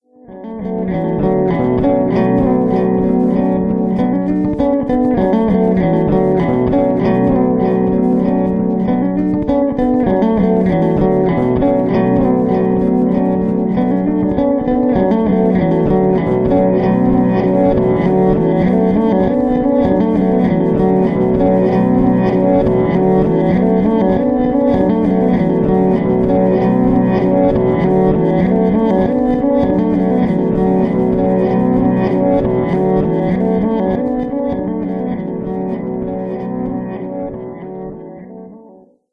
Music for empty rooms, spooling loops, and palindromes that don't quite work.